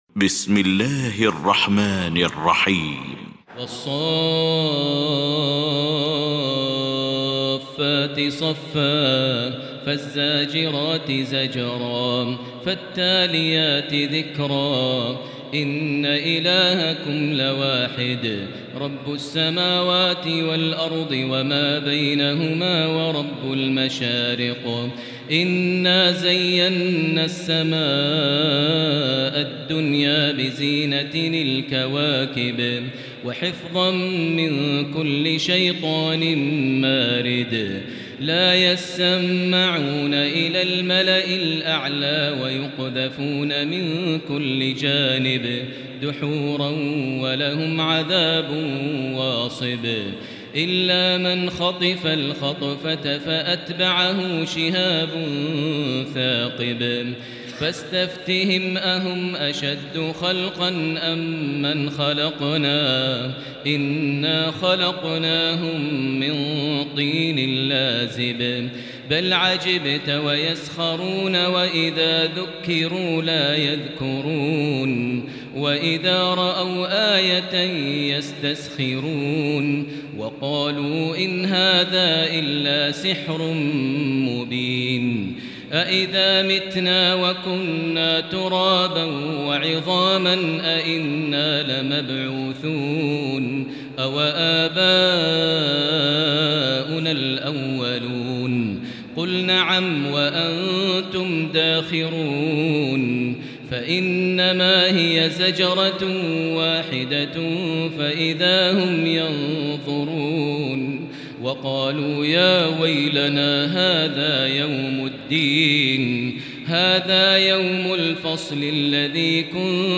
المكان: المسجد الحرام الشيخ: فضيلة الشيخ ماهر المعيقلي فضيلة الشيخ ماهر المعيقلي الصافات